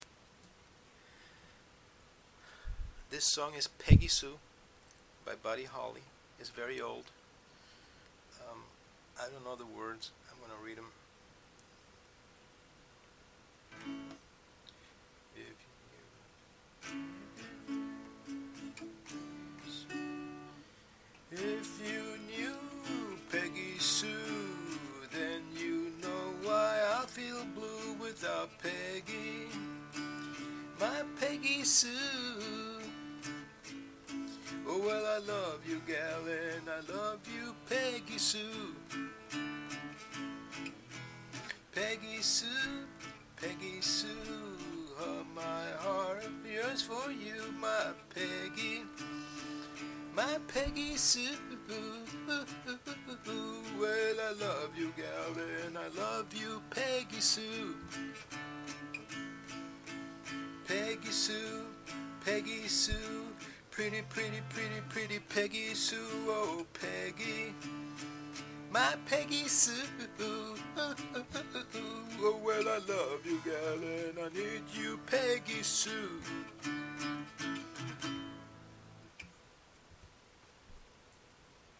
guitar practice beginner